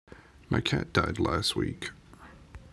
Cat